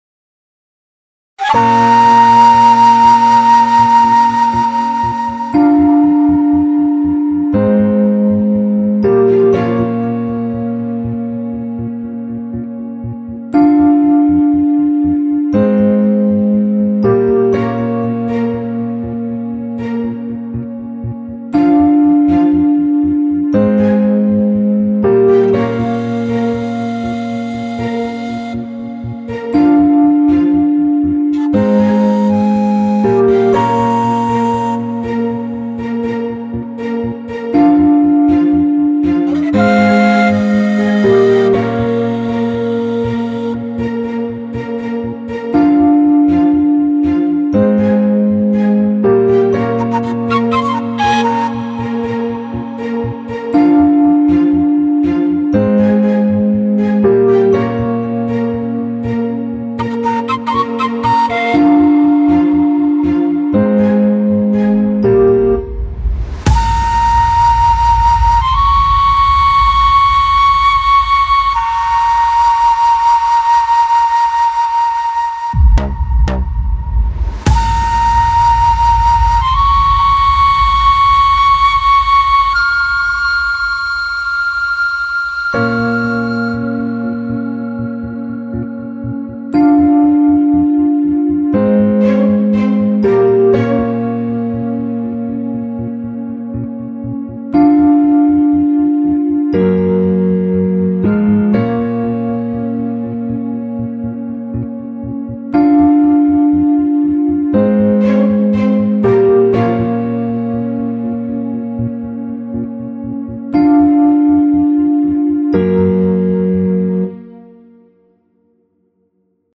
אני שמח להגיש לכם את השיר החמישי בסדרה האינסטרומנטלית שלי